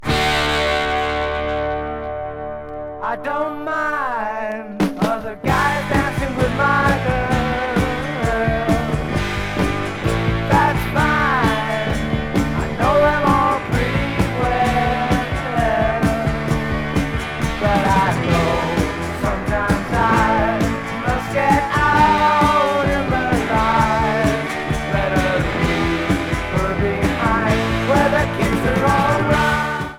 1975 Germany LP